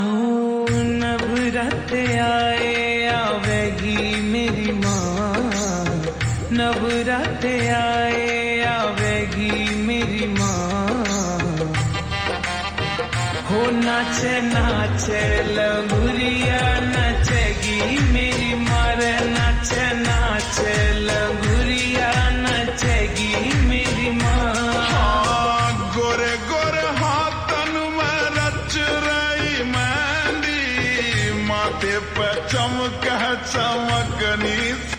Hindi Songs
Slow Reverb Version
• Simple and Lofi sound
• High-quality audio
• Crisp and clear sound